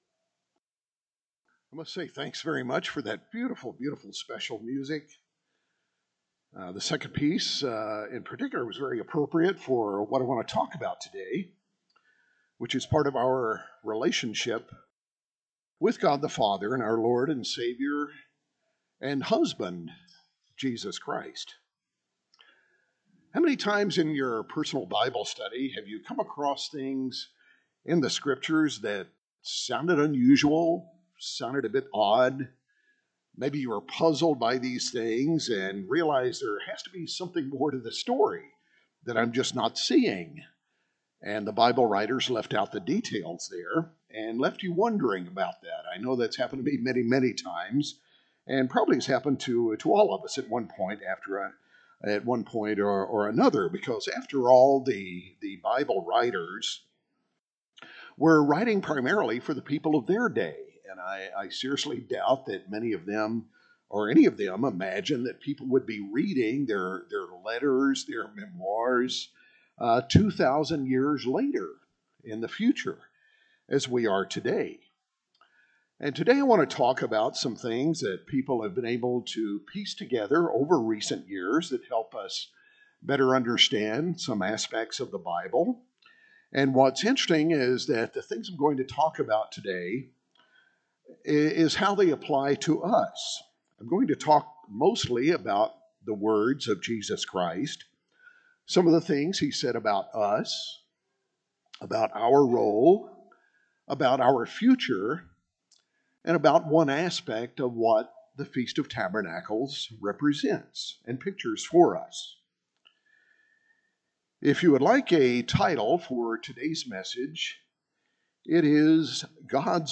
A Split sermon given at the Feast of Tabernacles in Glacier Country, Montana, 2020.
This sermon was given at the Glacier Country, Montana 2020 Feast site.